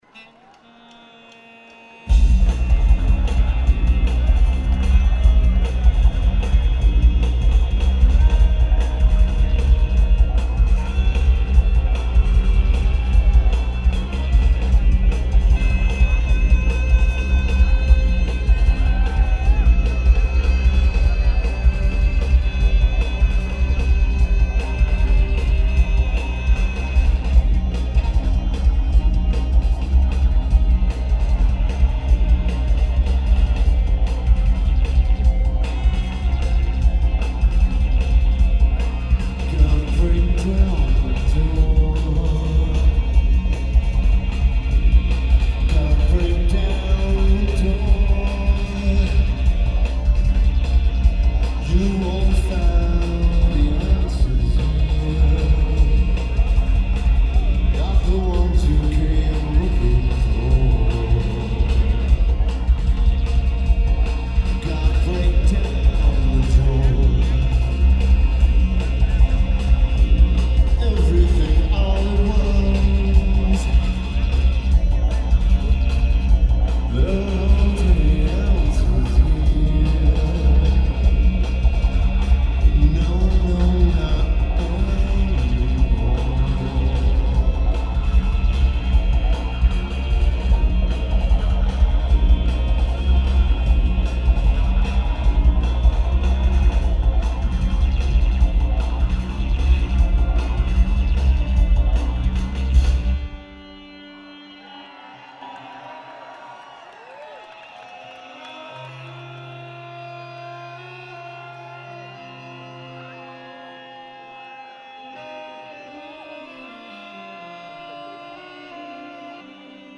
Santa Barbara Bowl
Drums
Guitar